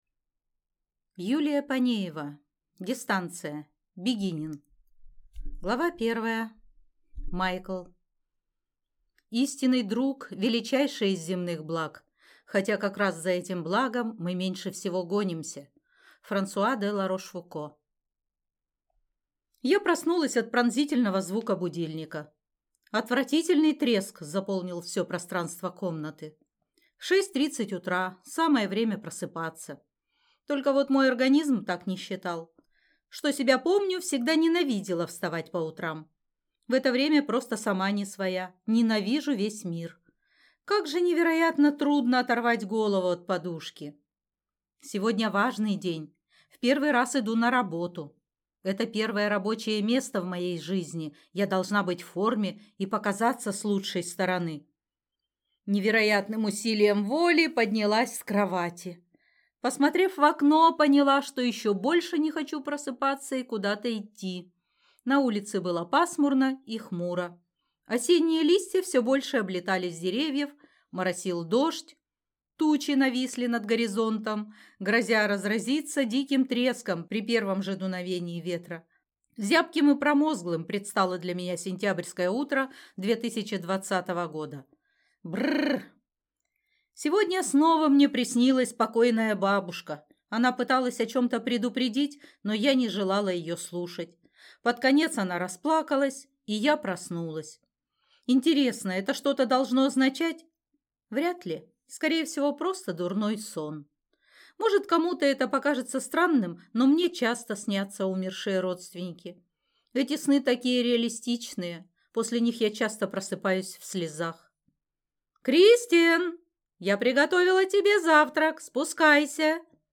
Аудиокнига Дистанция. Beginning | Библиотека аудиокниг